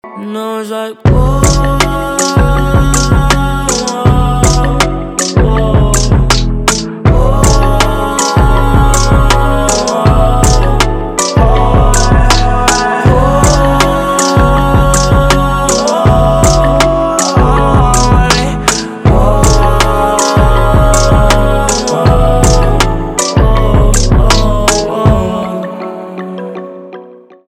поп
битовые
грустные , чувственные